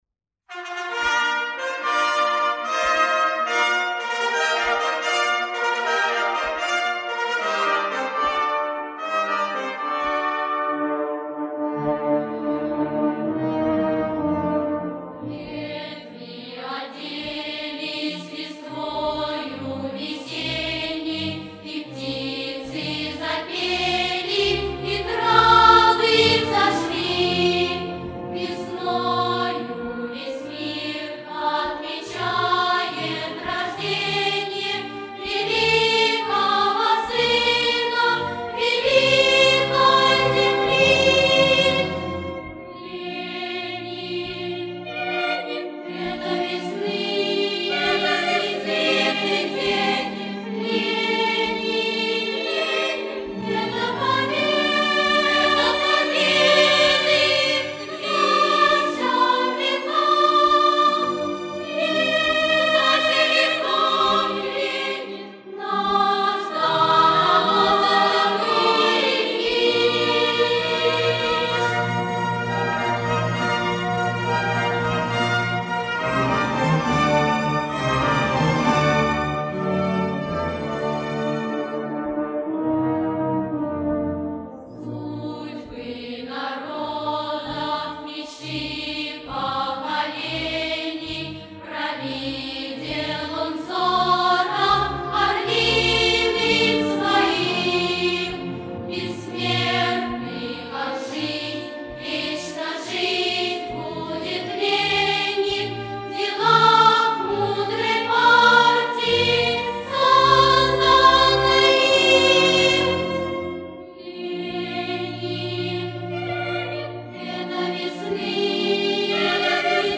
в исполнении детского хора.